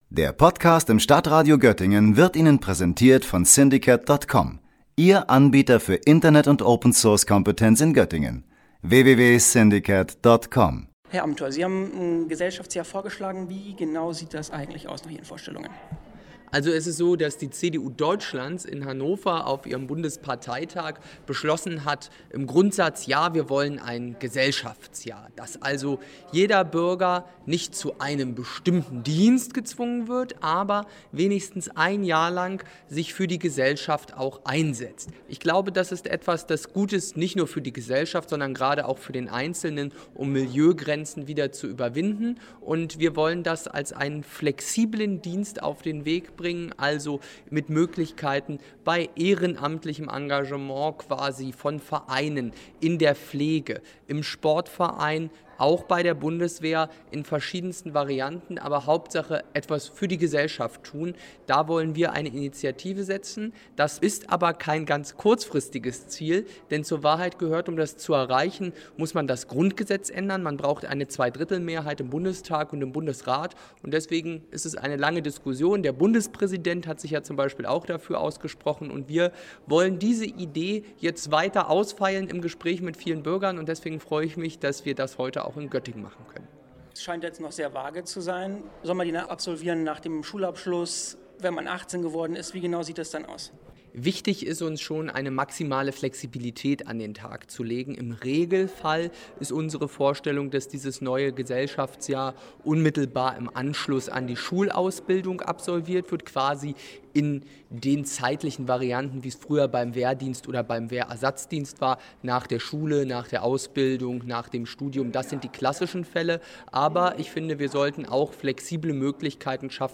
Beiträge > Philipp Amthor im Interview: Bürgergeld kürzen, um Gesellschaftsjahr zu finanzieren - StadtRadio Göttingen